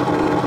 scrape loop 8.aiff